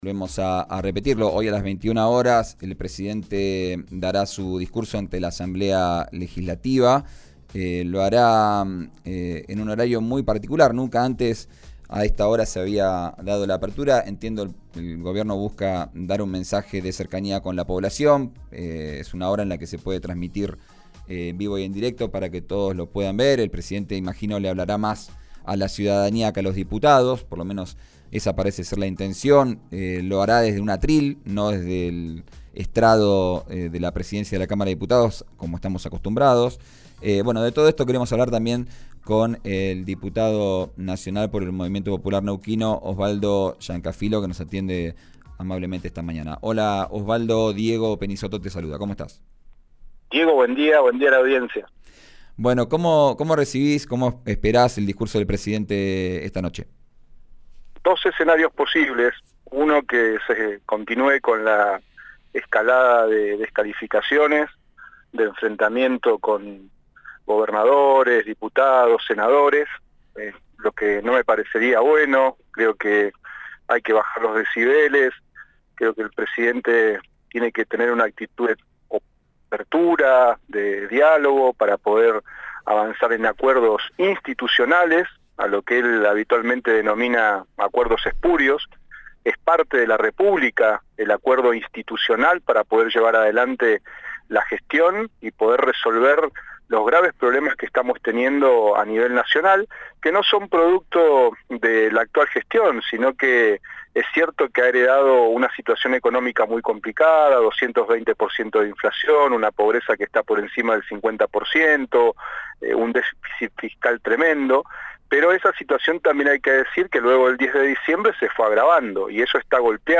El diputado nacional por el MPN destacó que el presidente brinde un discurso de cara al Congreso y no 'dándole la espalda', como en el acto de asunción. Escuchá la entrevista en RÍO NEGRO RADIO.
En comunicación con RÍO NEGRO RADIO, el diputado nacional por el Movimiento Popular Neuquino, Osvaldo Llancafilo, vaticinó «dos escenarios posibles».